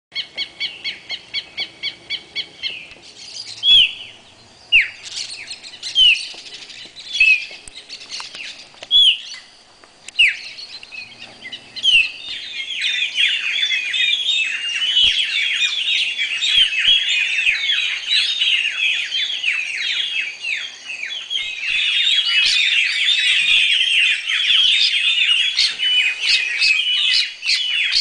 songbirds-in-brazil.mp3